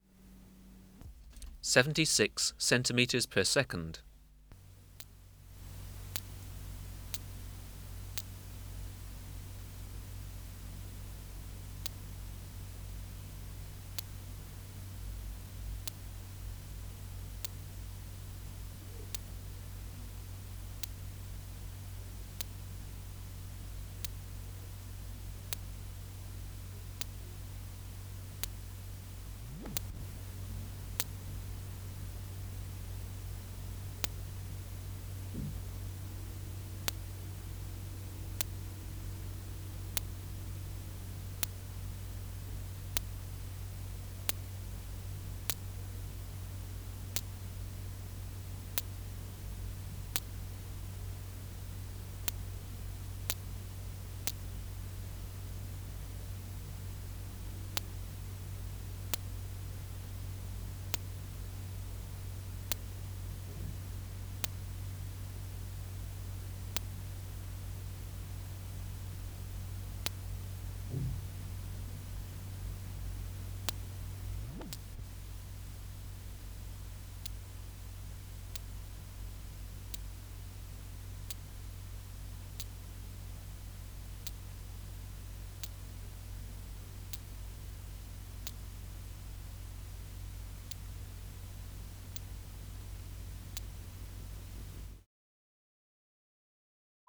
Recording Location: BMNH Acoustic Laboratory
Reference Signal: 1 kHz for 10 s
Substrate/Cage: Small recording cage Biotic Factors / Experimental Conditions: Isolated male
Microphone & Power Supply: Sennheiser MKH 405 Distance from Subject (cm): 10 Filter: Low pass, 24 dB per octave, corner frequency 20 kHz